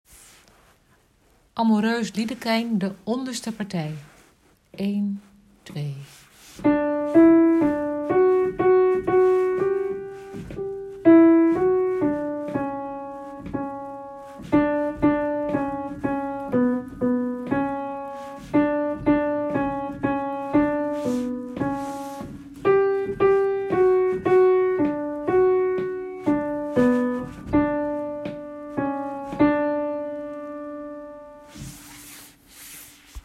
onderpartij